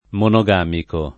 [ mono g# miko ]